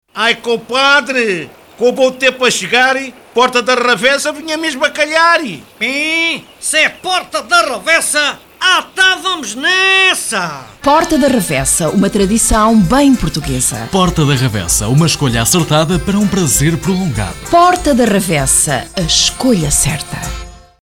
Desde sempre, os spots publicitários elaborados pelos Parodiantes comportam um formato de comunicação com sentido humorístico!